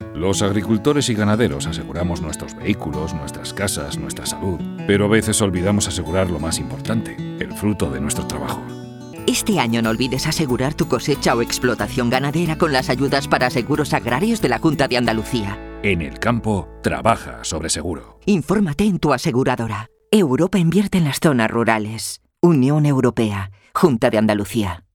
Cuña radiofónica
cuña seguros agrarios 25seg OK 3.mp3